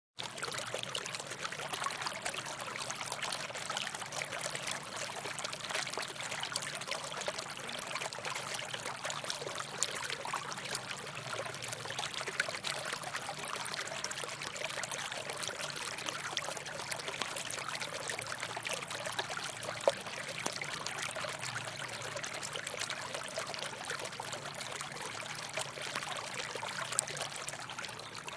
Fountain at Memorial Chapel Gardens